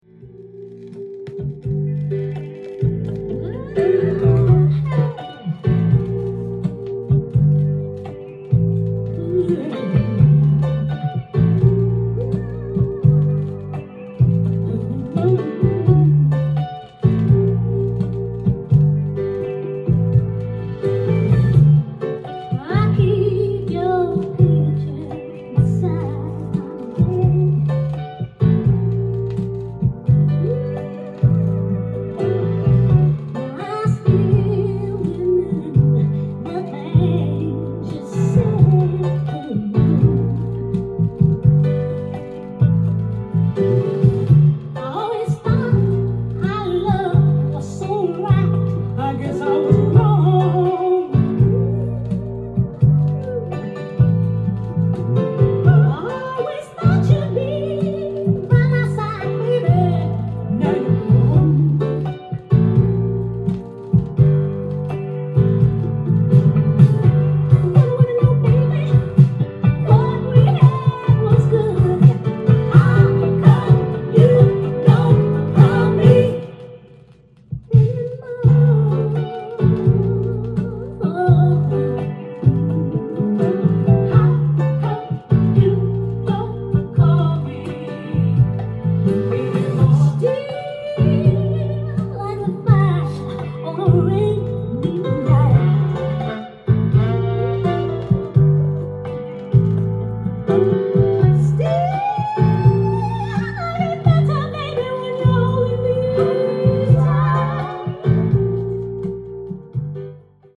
ジャンル：SOUL
店頭で録音した音源の為、多少の外部音や音質の悪さはございますが、サンプルとしてご視聴ください。
音が稀にチリ・プツ出る程度